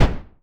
EXPLOSION_Subtle_Bang_stereo.wav